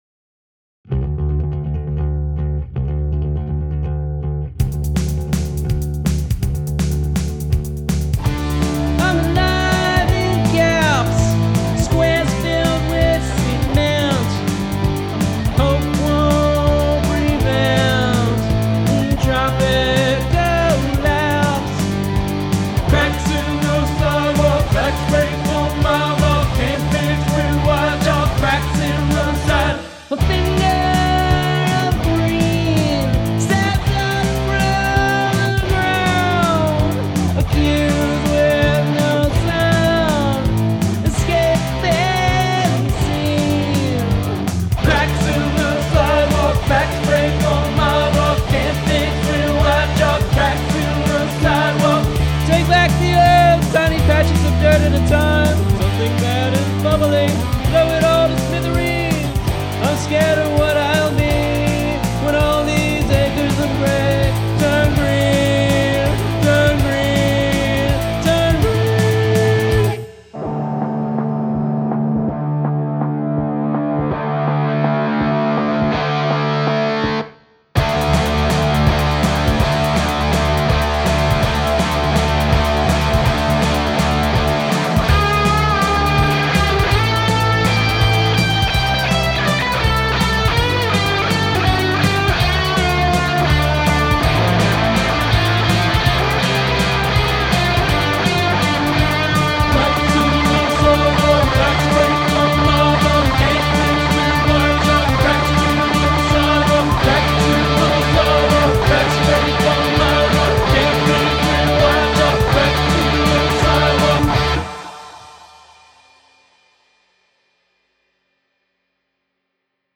Whole song is in 5/8; this was tough.